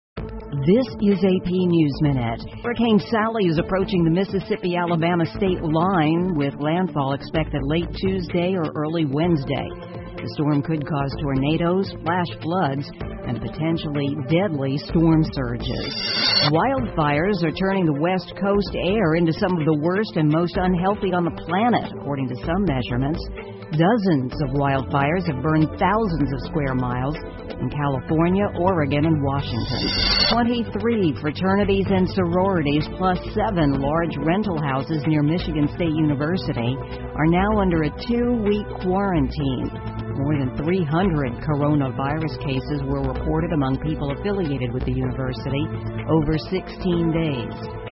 美联社新闻一分钟 AP 美国大学校园疫情爆发式增长 听力文件下载—在线英语听力室